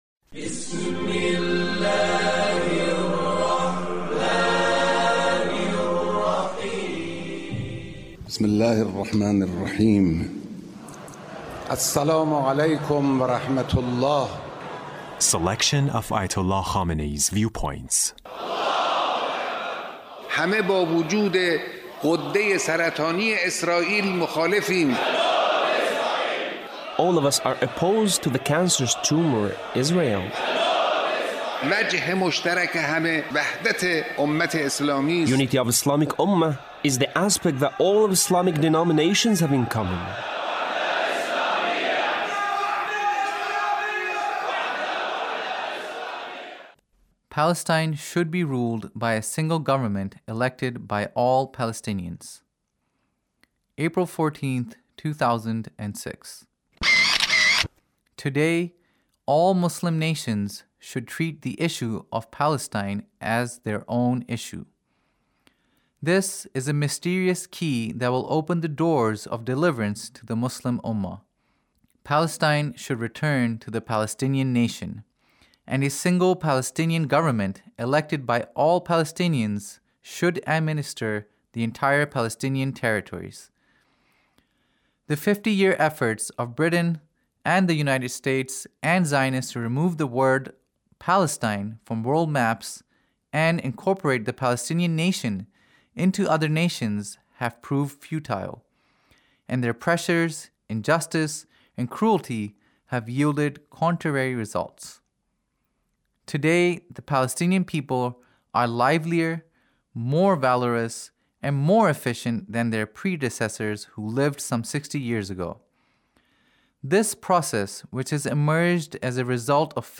Leader's Speech (1880)